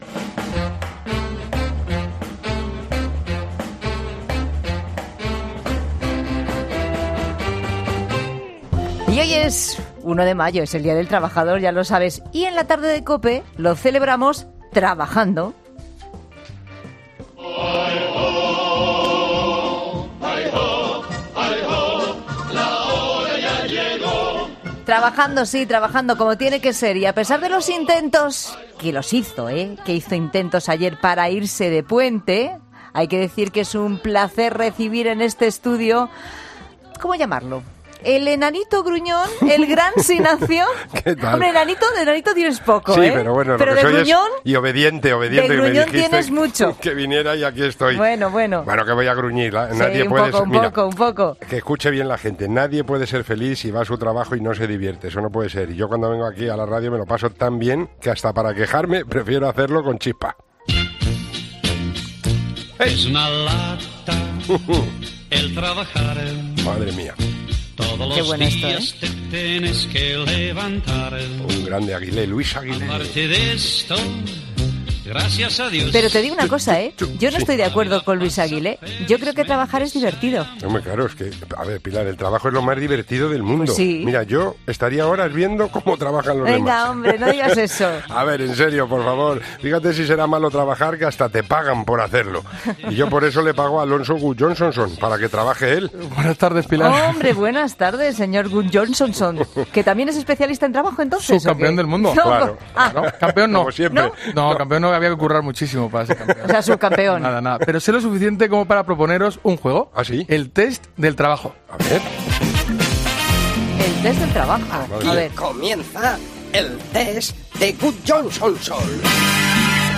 Escucharemos el audio de una entrevista para este complicado trabajo.